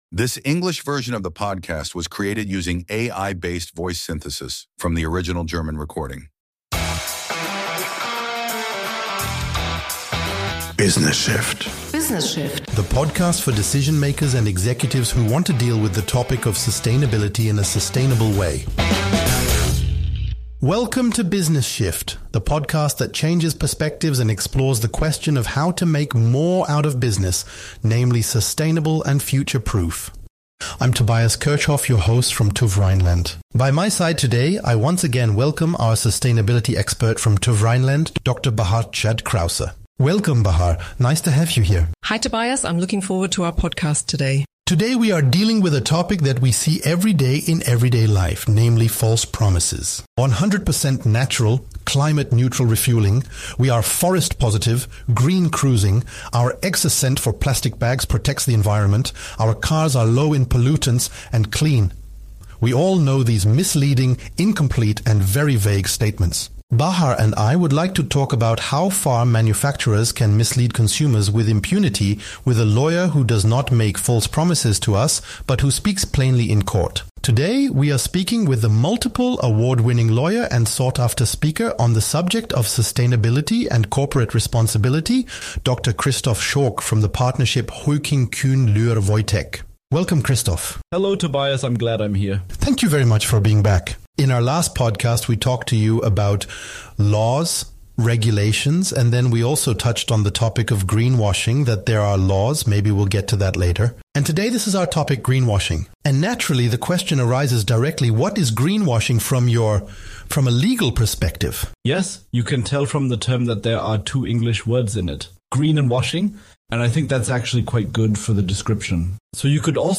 ****In compliance with AI regulations, we disclose that the English voices in this podcast episode were generated using artificial intelligence based on the original German version.*** Mehr